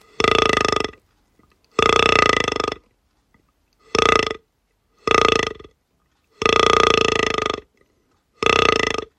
The Oakwood Fallow Grunt – authentic sound, rut-ready performance.
Oakwood-Fallow2.mp3